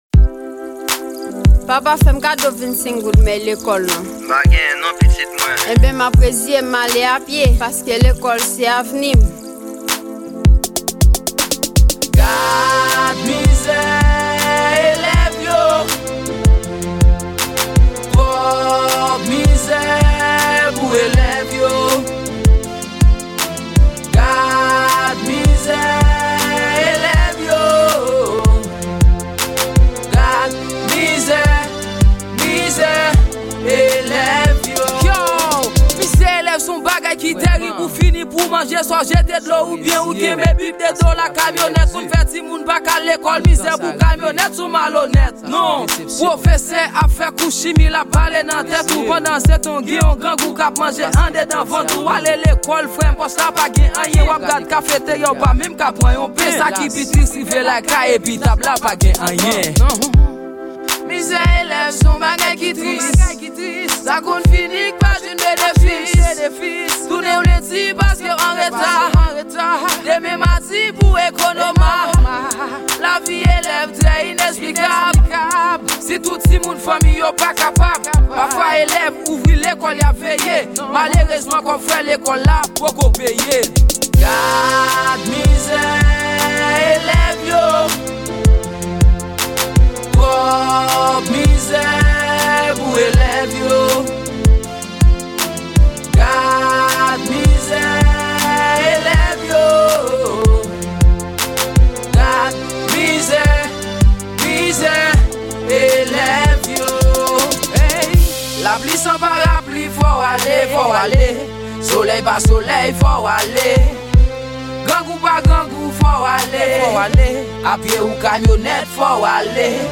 Genre : rap